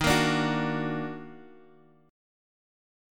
EbmM7 chord